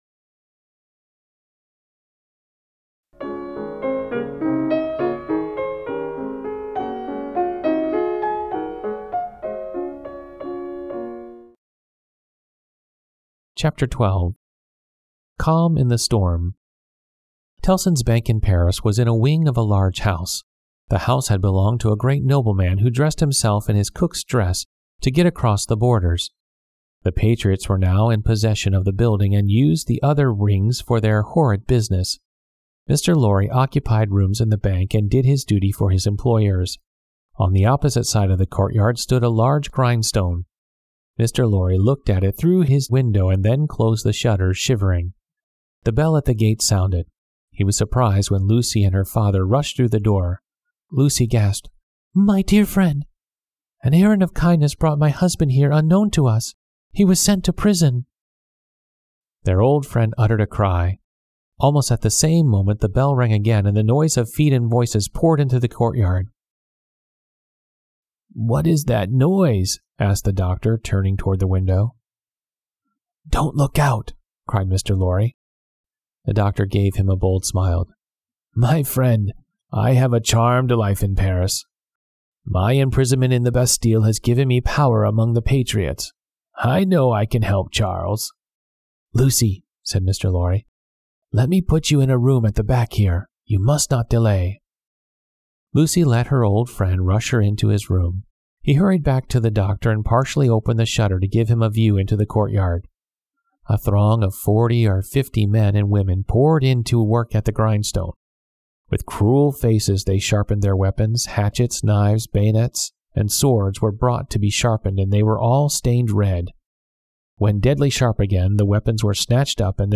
丛书甄选优质中文译本，配以导读、作家作品简介和插图，并聘请资深高考听力卷主播朗读英语有声书。